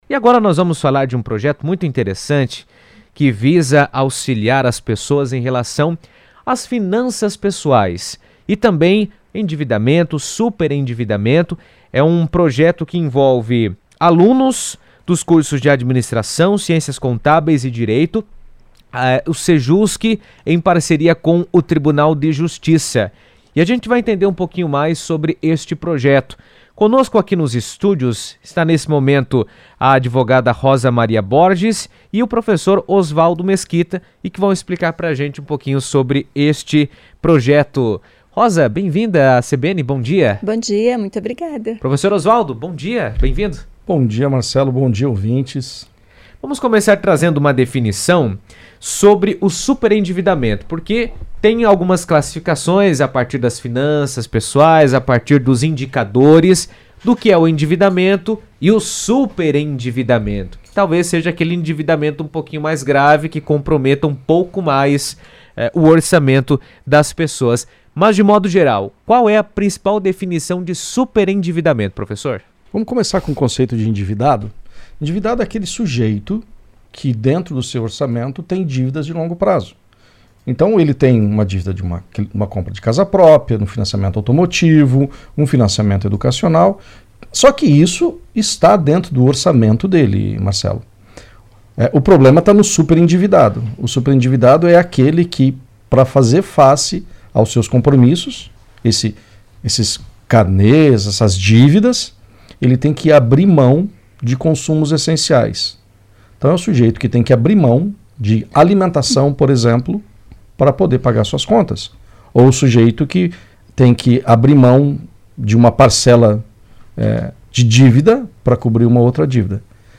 O CEJUSC, em parceria com o Tribunal de Justiça, está desenvolvendo um projeto de extensão voltado à educação financeira e ao combate ao superendividamento, com alunos dos cursos de Administração, Ciências Contábeis e Direito. Em entrevista à CBN